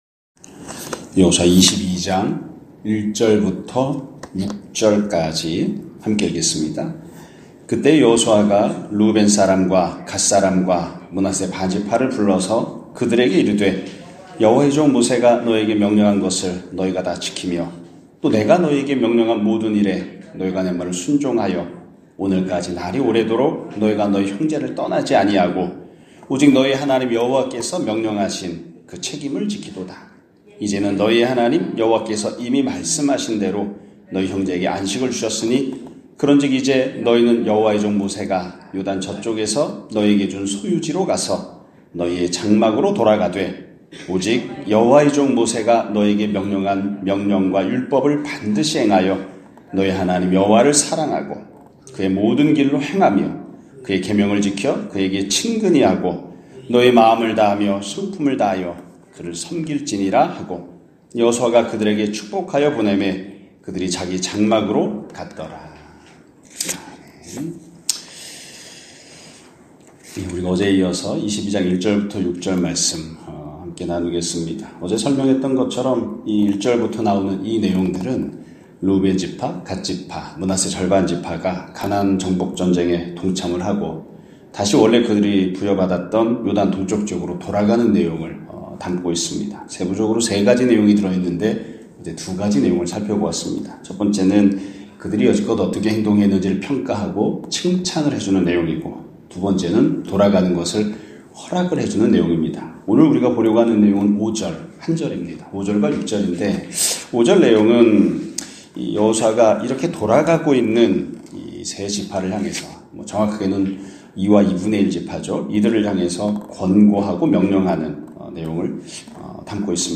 2025년 2월 6일(목 요일) <아침예배> 설교입니다.